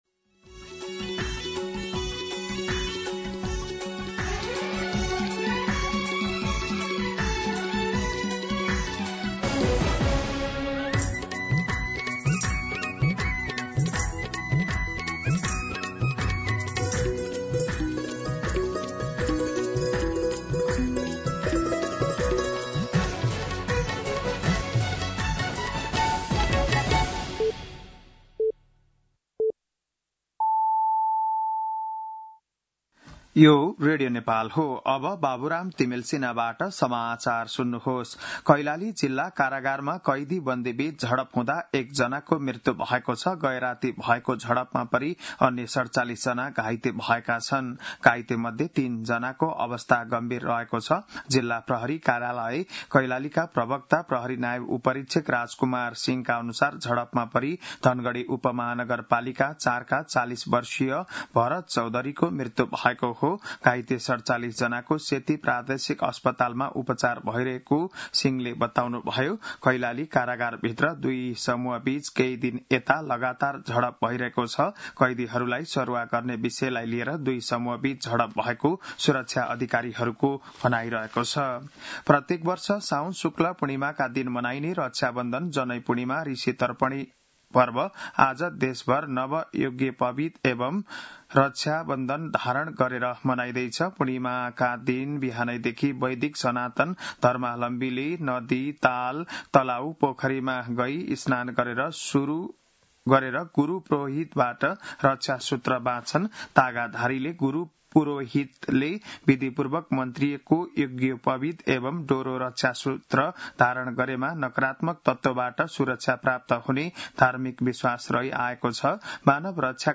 बिहान ११ बजेको नेपाली समाचार : २४ साउन , २०८२
day-news-11am.mp3